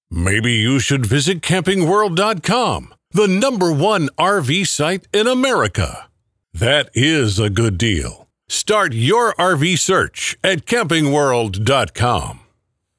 Male
Television Spots
Rv Tv Spot Tag